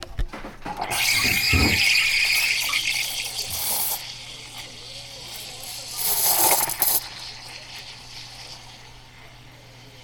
We ended up recording our sounds using the zoom voice recorder.
Human world (coffee steamer):